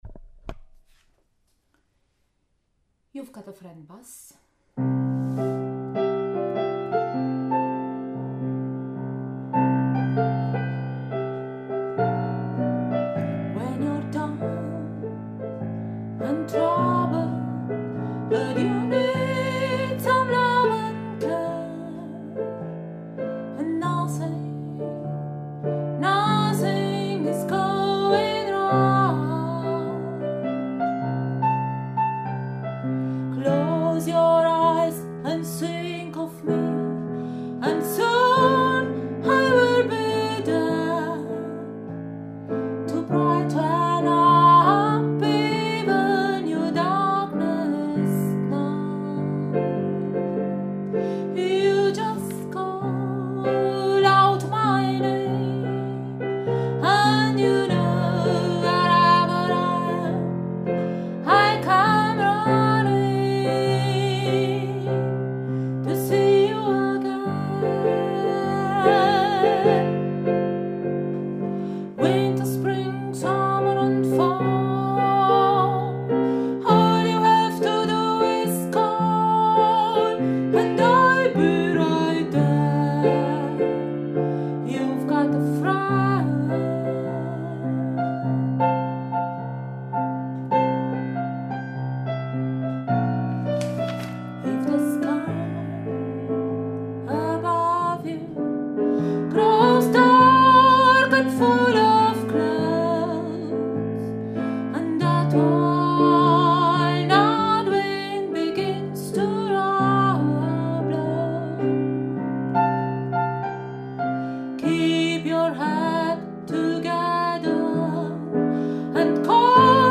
You’ve got a friend – Bass
Bass-Youve-got-a-friend.mp3